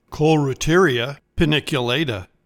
Pronounciation:
Cole-reu-TEAR-ee-a pan-ic-u-LA-ta